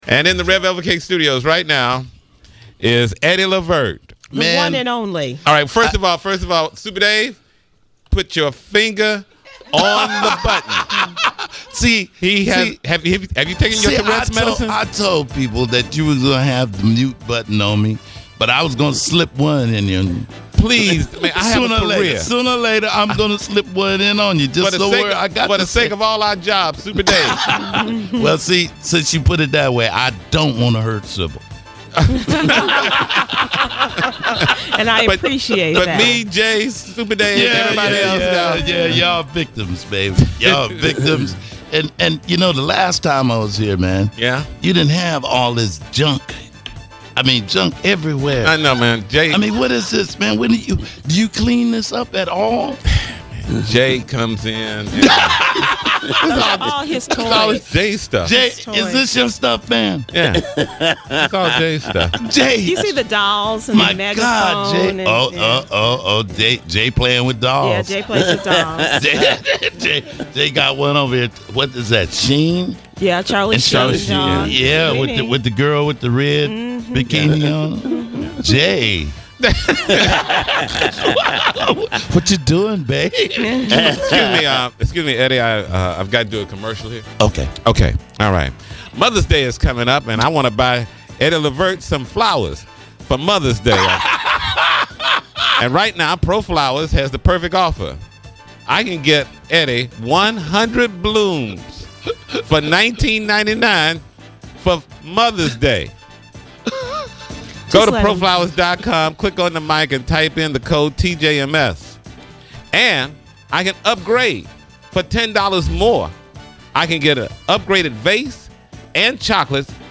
TJMS: Eddie Levert Stops By And Does "In Studio Jam" [AUDIO]
The O’Jays lead singer and father of deceased RnB singing great Gerald Levert, Eddie Levert comes to the Tom Joyner Morning Show. He talks about his music, which is currently available now and performs the “In Studio Jam”.